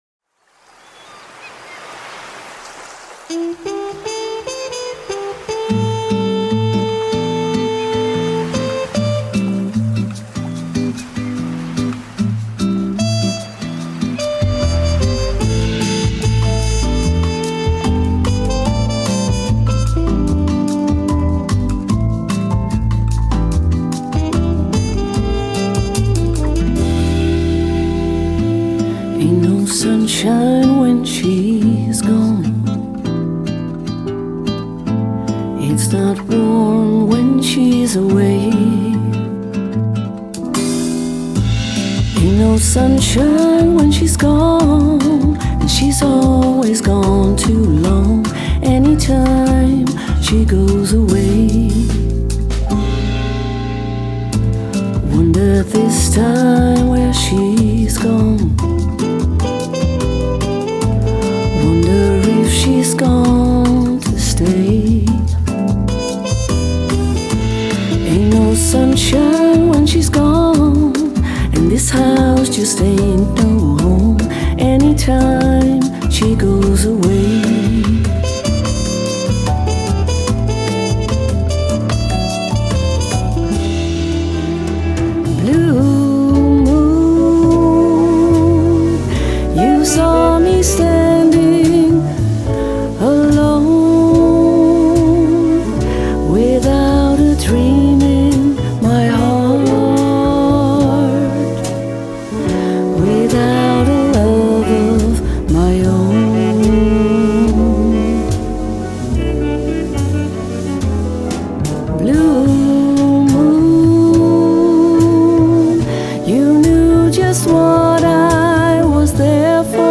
Slow Jazz en Bossa Nova covers
populaire liedjes in een zacht en stijlvol Bossa Nova jasje.